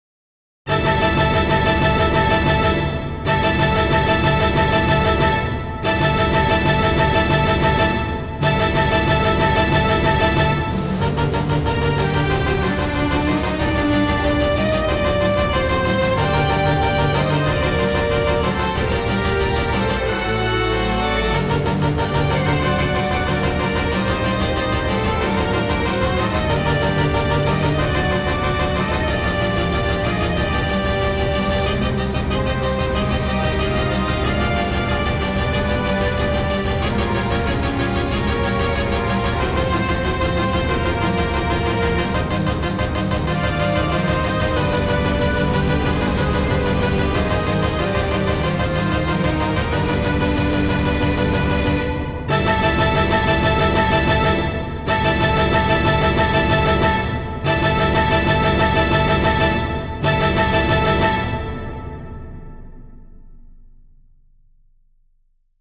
For Symphony
GET-TO-IT-Symphony.mp3